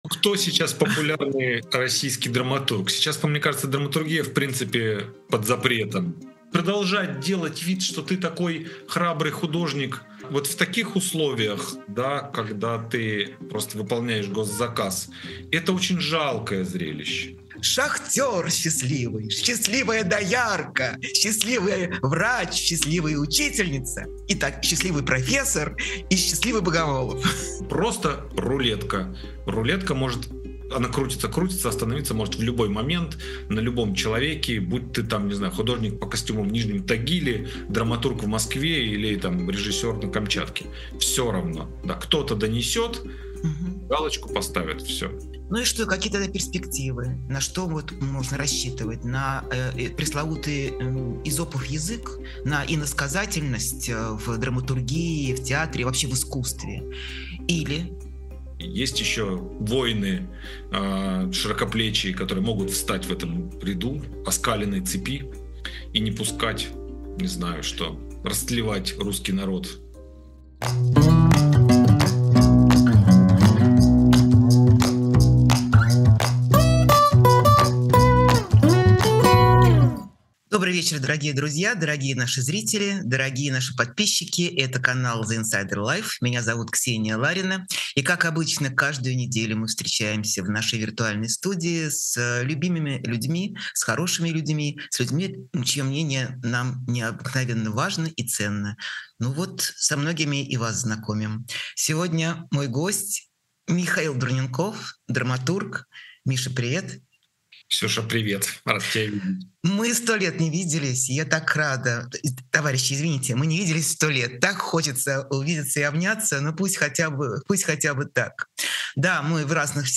Эфир ведёт Ксения Ларина
Новый выпуск программы «Честно говоря» с Ксенией Лариной. Говорим с драматургом, сценаристом Михаилом Дурненковым о самоцензуре и свободе в театре, деле Беркович и Петрийчук и переживании травмы эмиграции.